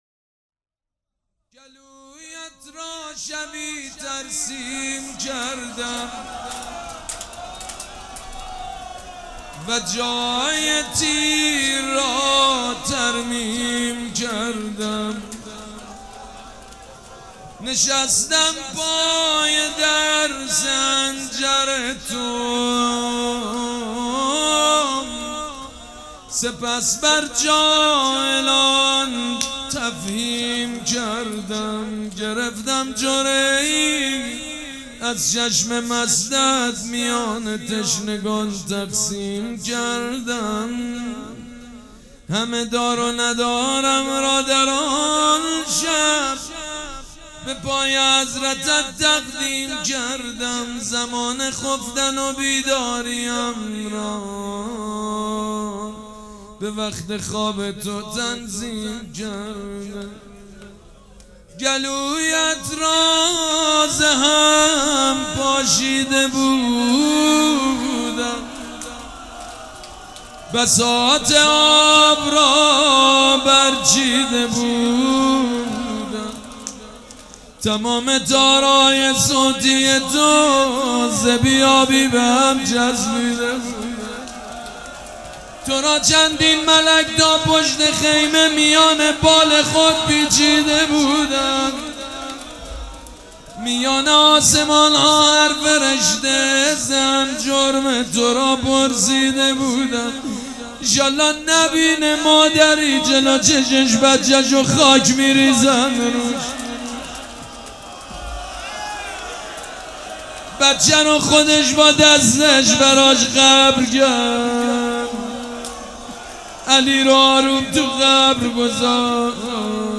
روضه favorite
سبک اثــر روضه مداح حاج سید مجید بنی فاطمه
مراسم عزاداری شب هفتم